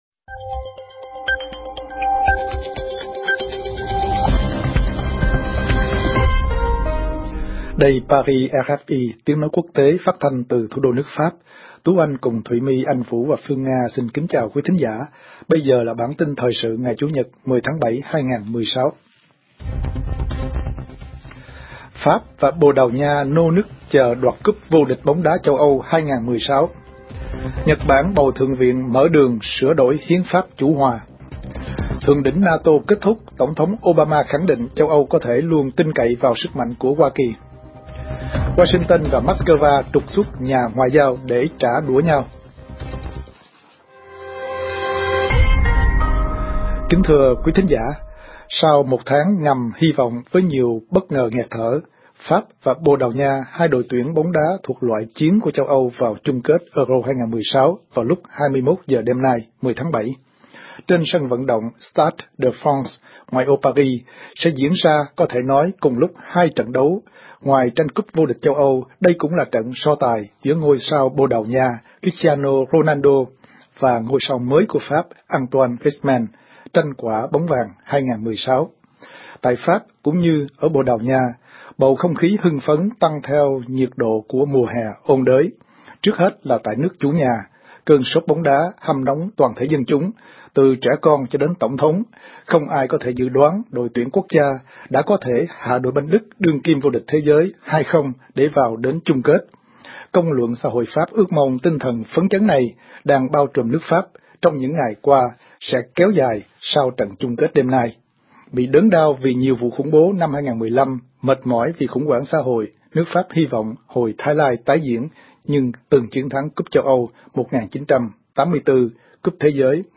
Chia sẻ lên X Chia sẻ lên Facebook Chia sẻ lên Pinterest MỤC : AUDIO - NGHE CHƯƠNG TRÌNH PHÁT THANH